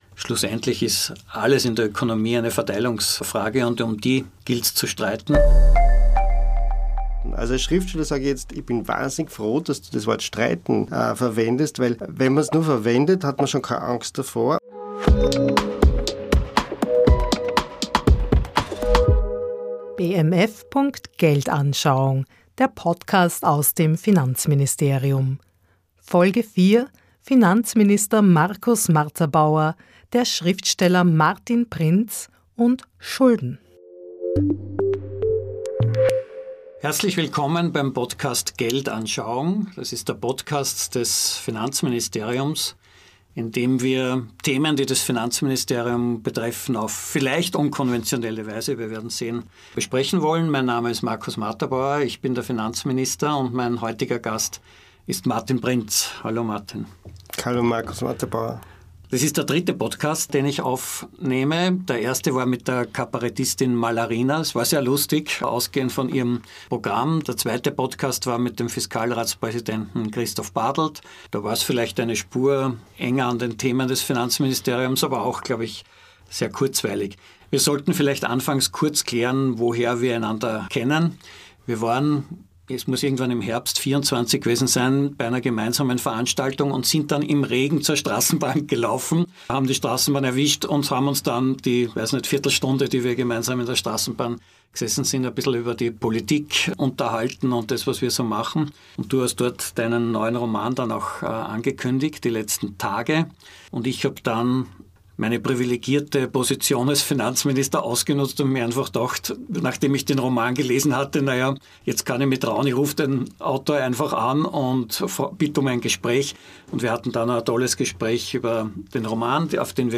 Ein Gespräch über Schuld und Schulden, Defizite, Macht, Verteilungsfragen und welche Bücher John Maynard Keynes heute schreiben würde.